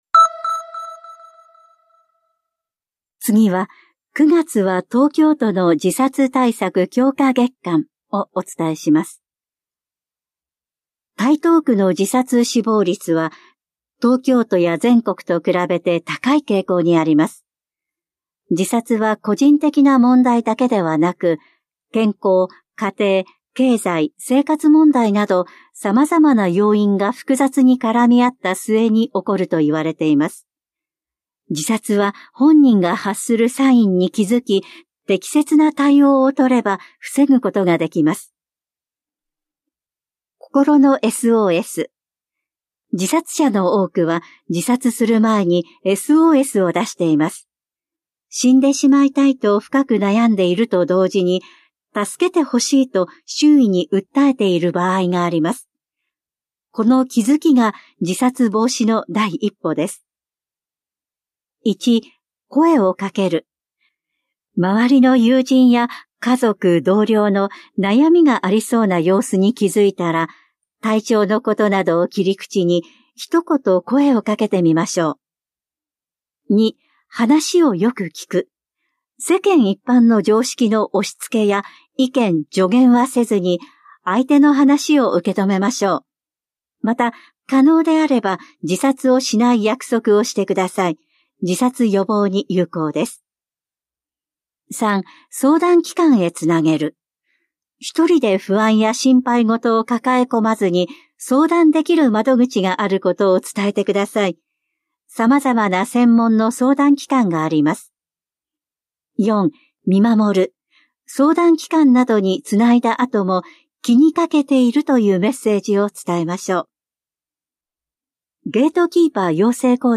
広報「たいとう」令和6年9月5日号の音声読み上げデータです。